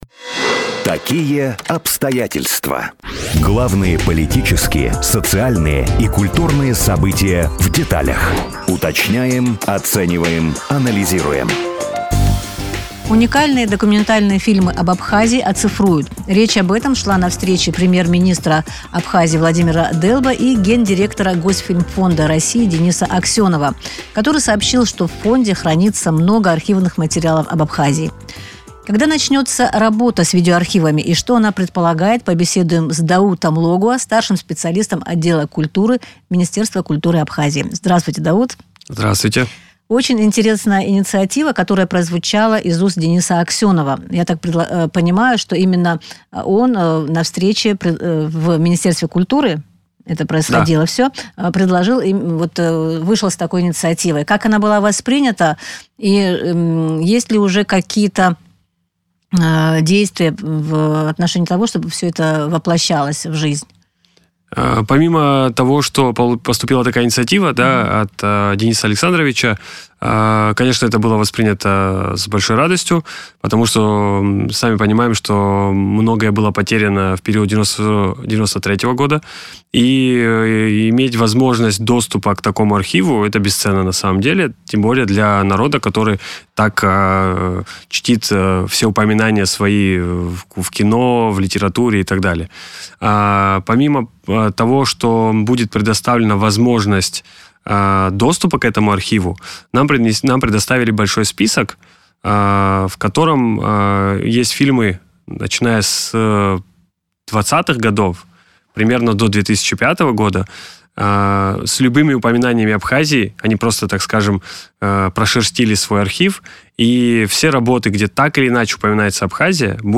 в эфире радио Sputnik